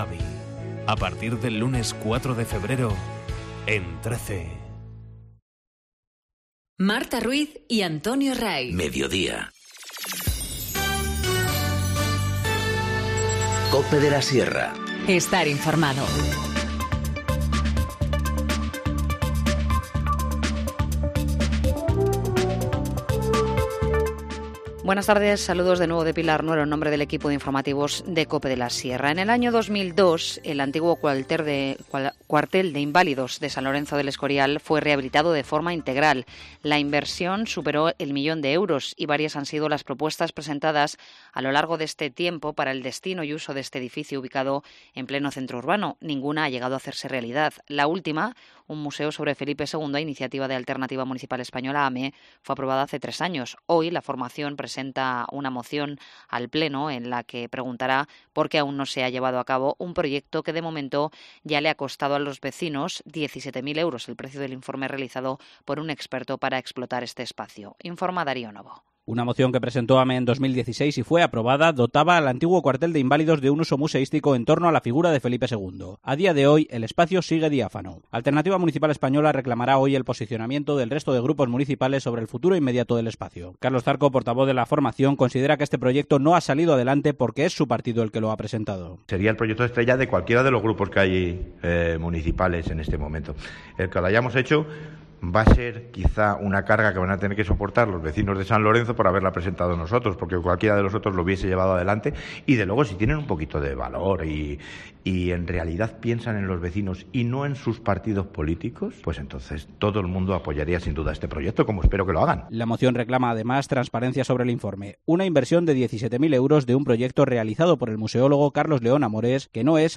Informativo Mediodía 31 enero- 14:50h